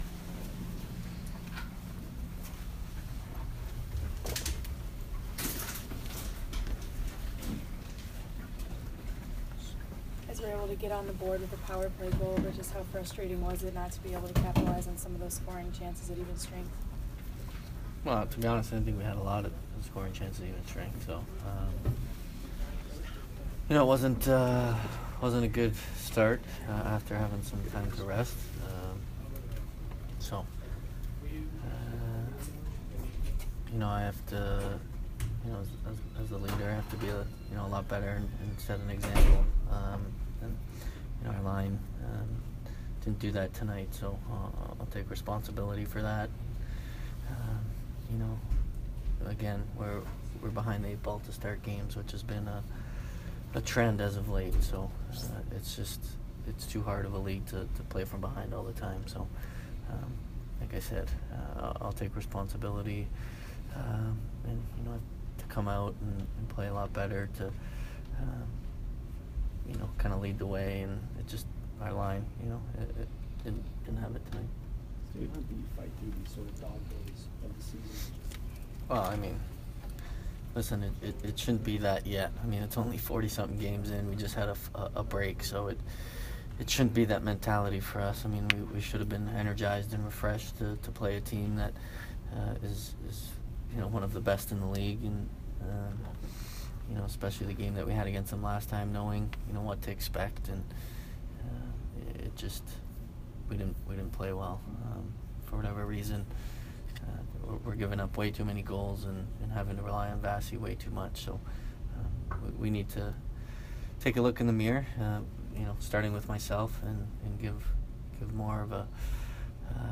Steven Stamkos Post-Game 1/18